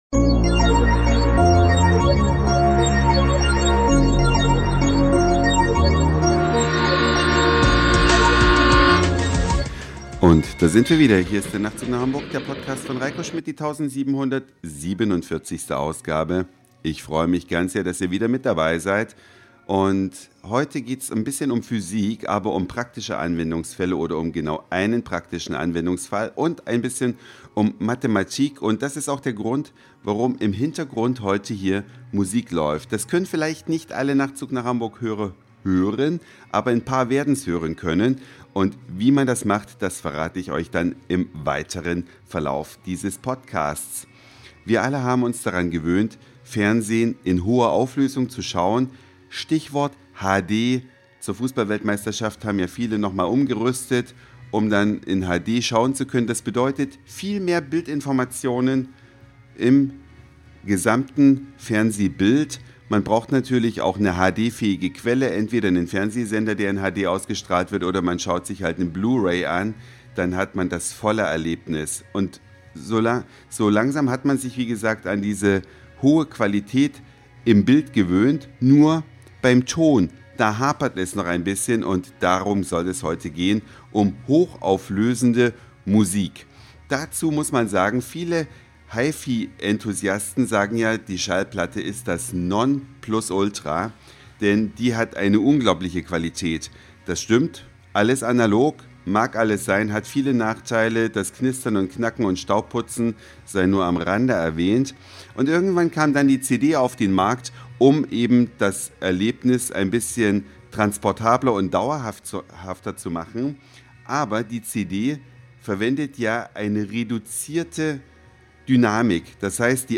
Musik in nie gehörter Qualität Der Nachtzug nach Hamburg als Studio